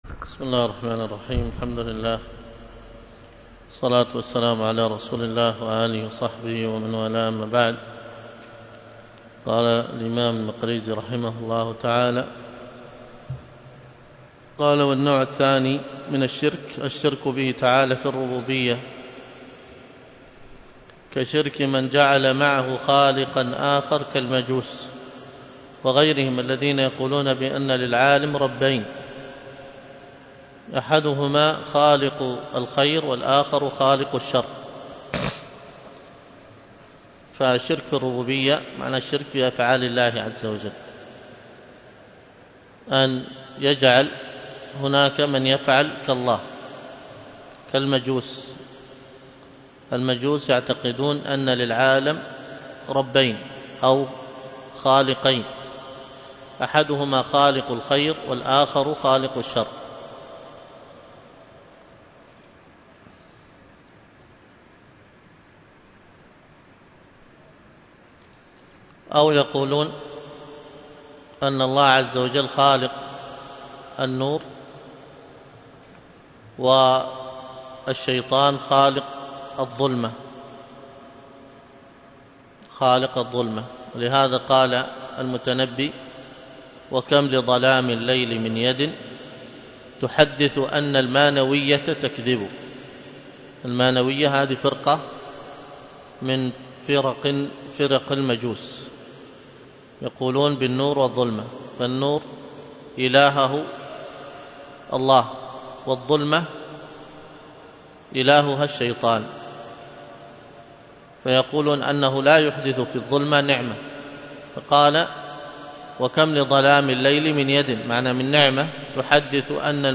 الدروس العقيدة ومباحثها